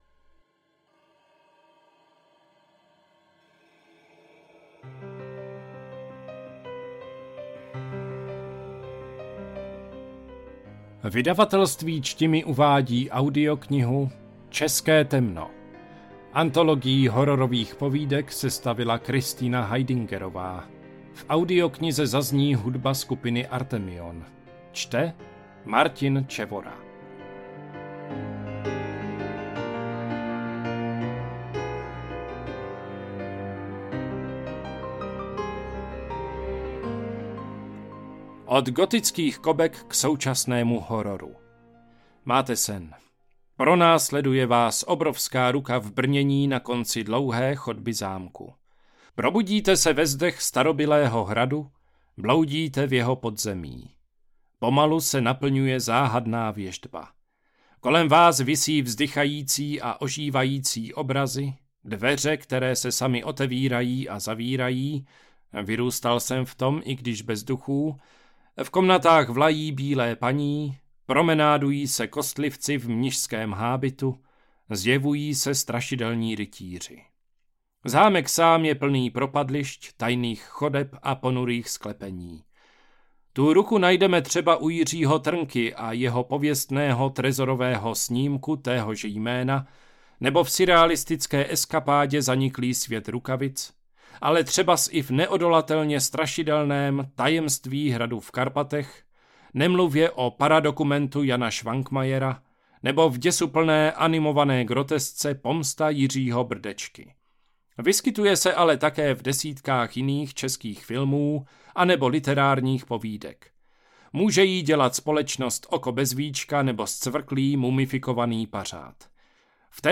Kategorie: Horor
Budeme rádi, když s pomocí recenze dáte ostatním vědět, jak se vám audiokniha líbila.